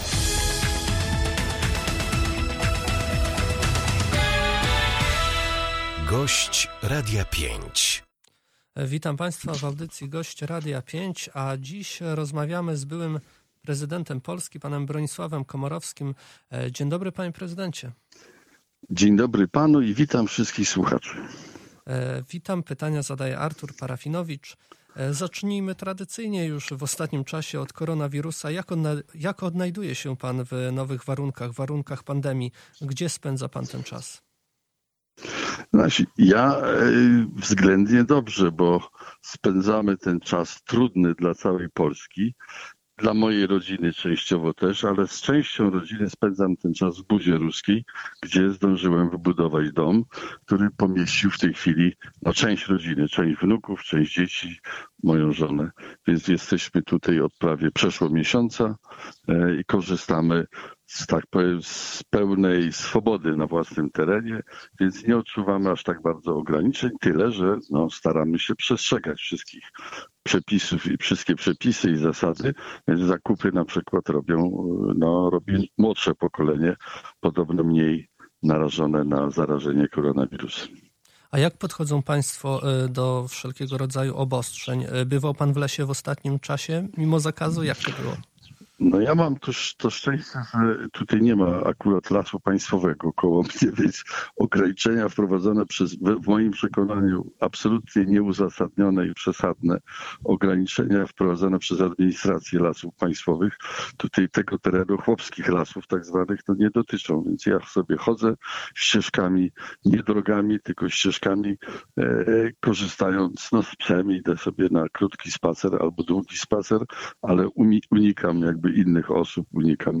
Bronisław Komorowski gościem Radia 5
O tym jak radzi sobie w czasie pandemii i przepisach łowieckich mówił dziś w Radiu 5 Bronisław Komorowski, były prezydent RP, mieszkaniec Suwalszczyzny. Nie zabrakło też pytania o zmarłego niedawno profesora Andrzeja Strumiłłę, zasłużonego artystę.